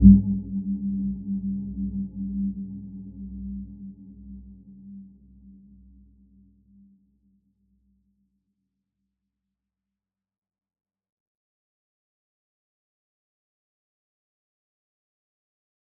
Dark-Soft-Impact-G3-f.wav